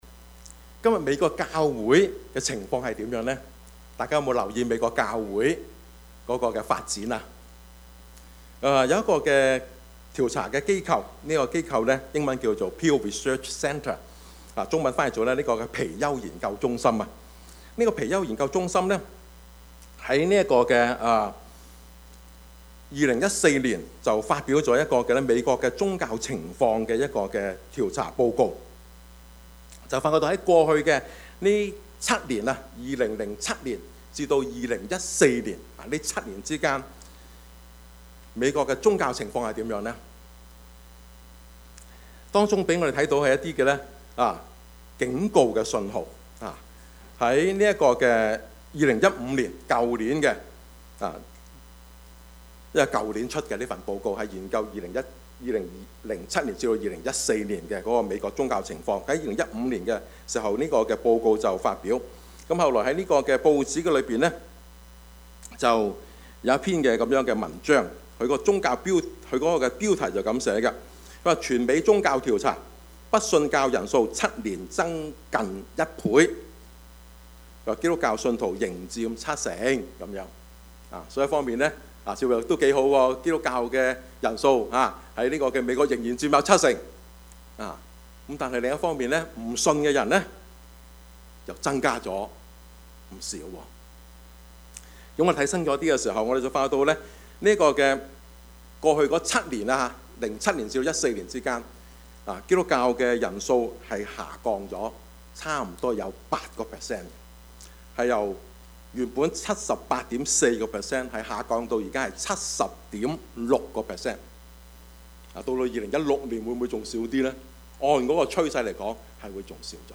Service Type: 主日崇拜
Topics: 主日證道 « 健康成長的教會 智慧人與愚昧人 »